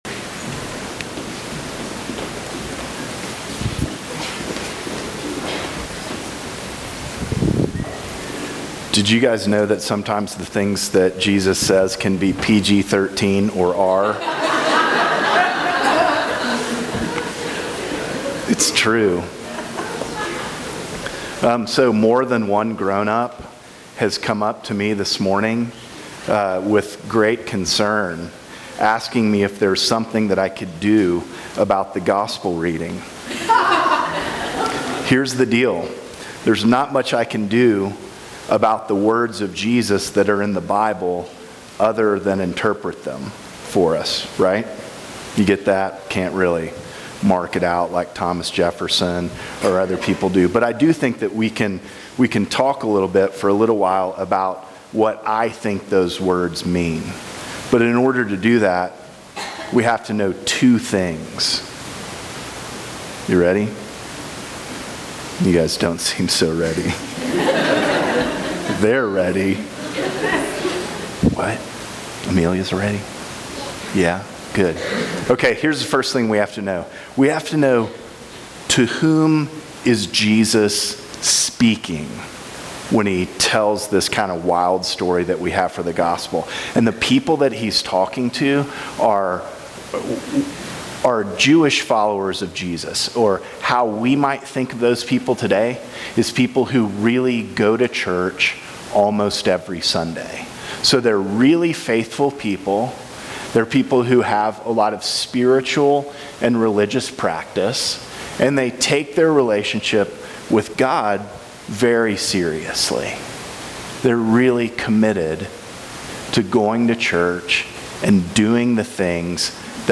Children's Sermon: Deuteronomy 30:15-20; 1 Corinthians 3:1-9; Matthew 5:21-37; Psalm 119:1-8